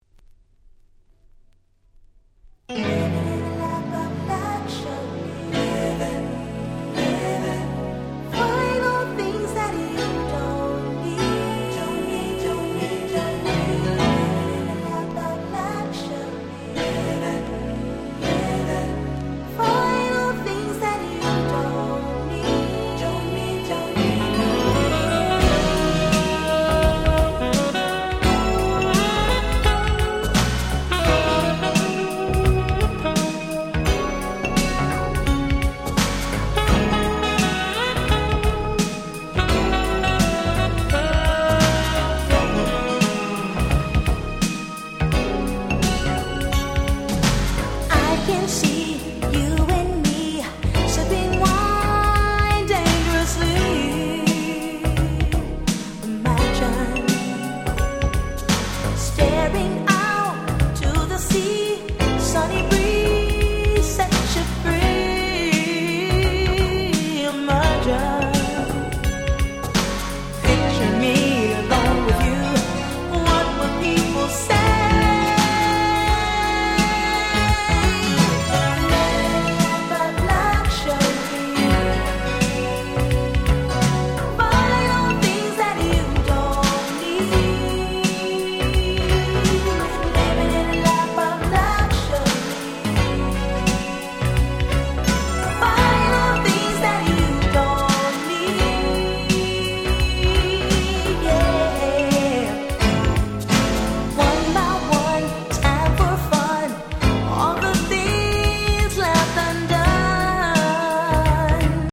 88' Super Nice UK R&B !!